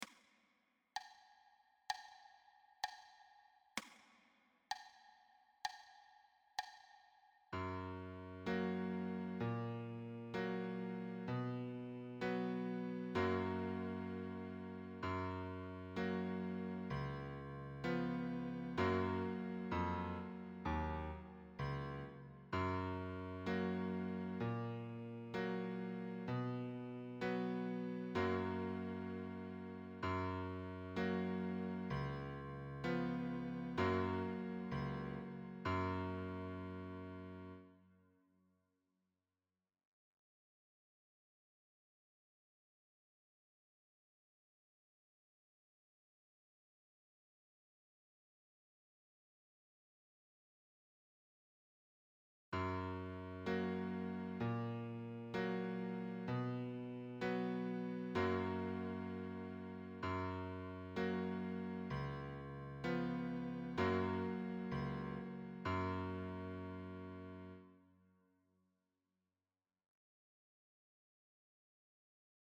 Lehrerbegleitung